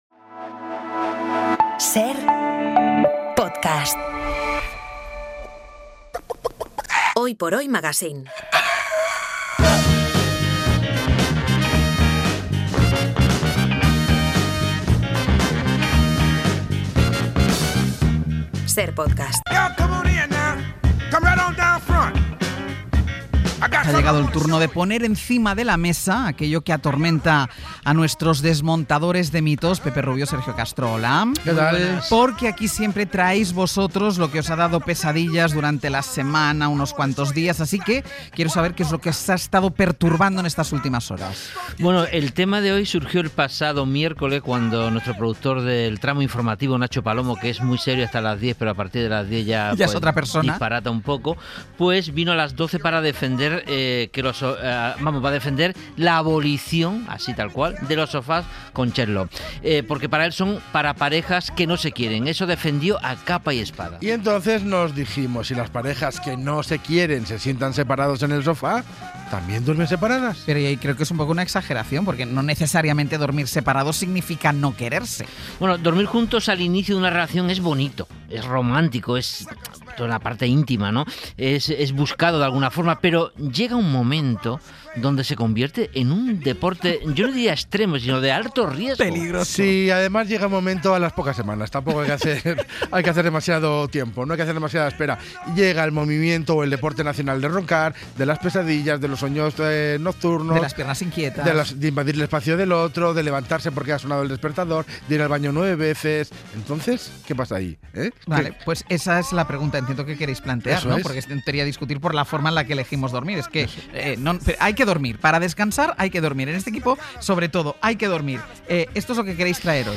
una colaboración del programa Hoy por Hoy de Cadena SER